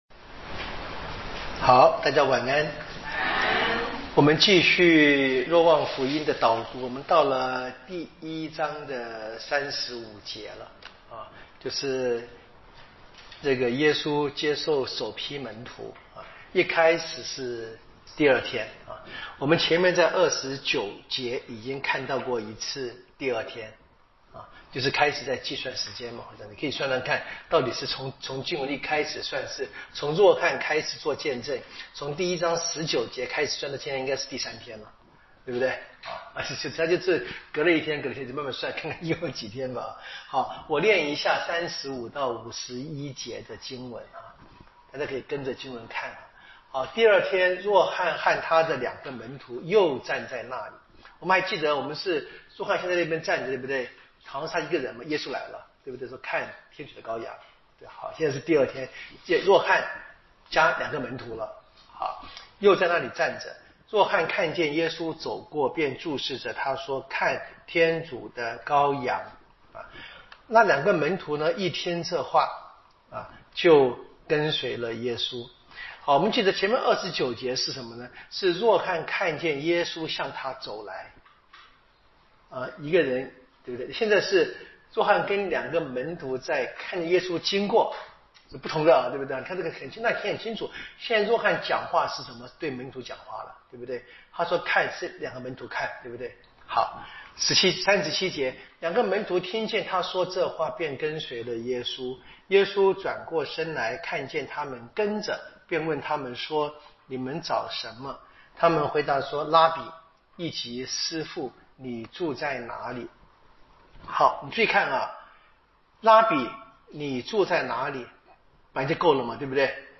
【圣经讲座】《若望福音》